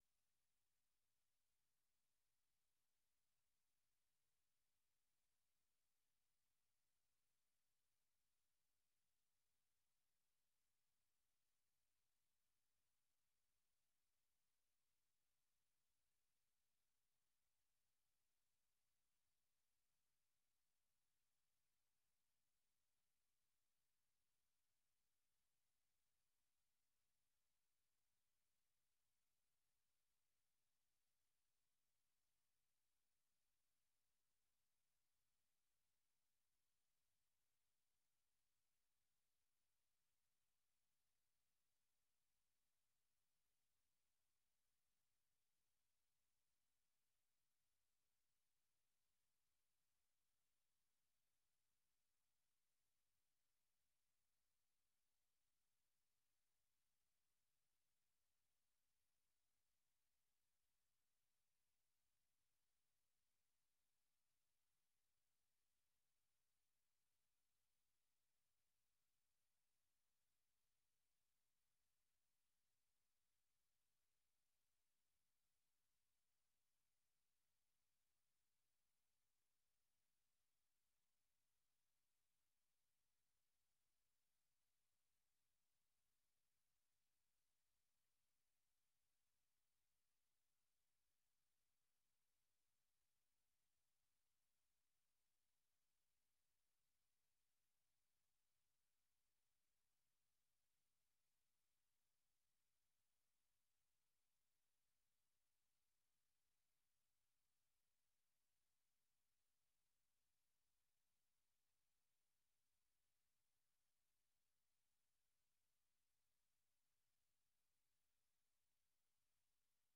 د نن او وضعیت په خپرونه کې د افغانستان د ټولنیز او فرهنګي وضعیت ارزونه ددې خپرونې له میلمنو څخه اورئ. دغه خپرونه هره شپه د ٩:۳۰ تر ۱۰:۰۰ پورې په ژوندۍ بڼه ستاسې غږ د اشنا رادیو د څپو او د امریکا غږ د سپوږمکۍ او ډیجیټلي خپرونو له لارې خپروي.